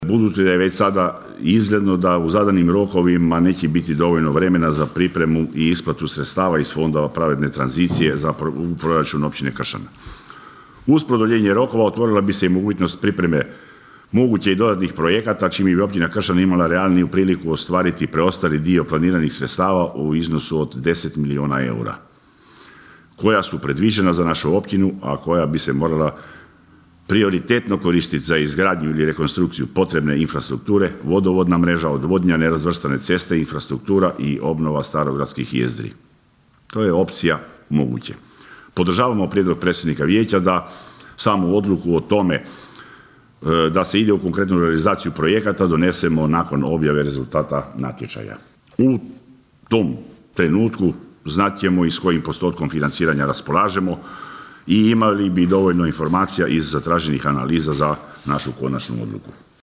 Budući da nismo željeli preuzeti odgovornost i izglasati predloženi Proračun bez potpune i jasne slike o navedenim projektima, zatražili smo radni sastanak kako bismo dobili dodatna pojašnjenja i odgovore na naša pitanja“, pojasnio je sinoć na sjednici nezavisni vijećnik Valdi Runko.